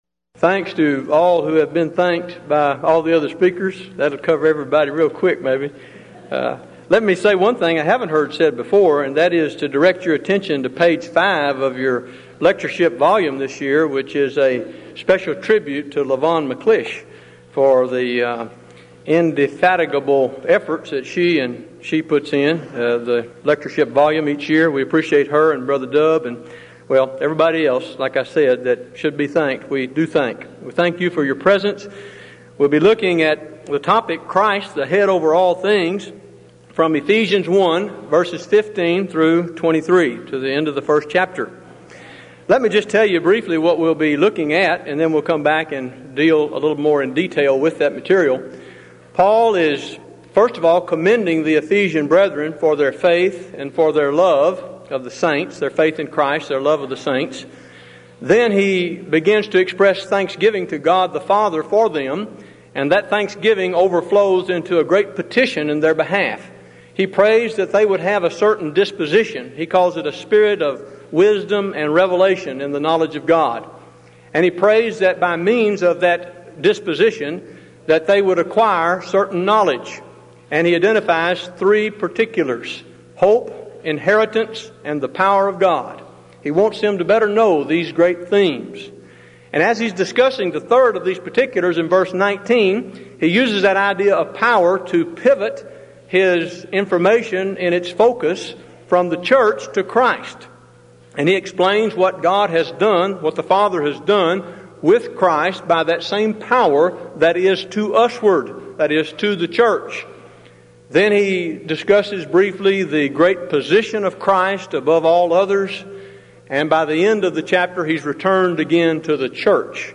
Event: 16th Annual Denton Lectures Theme/Title: Studies In Ephesians